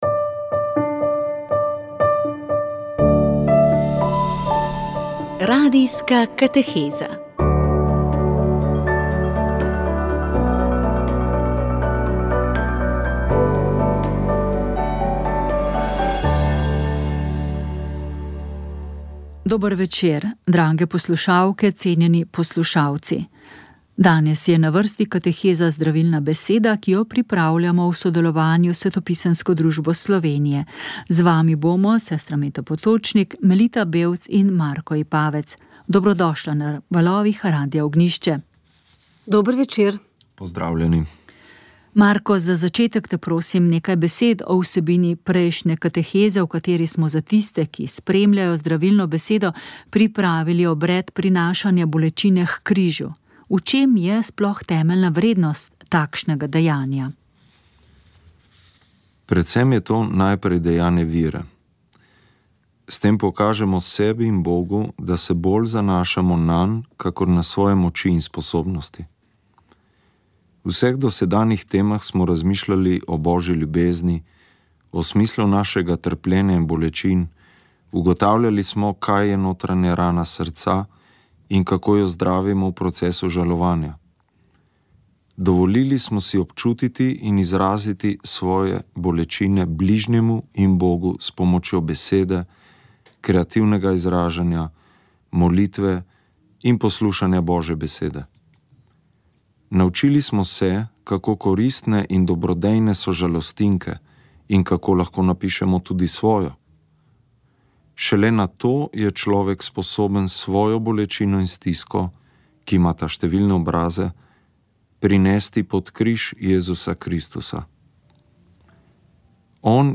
Rožni venec
Molili so radijski sodelavci.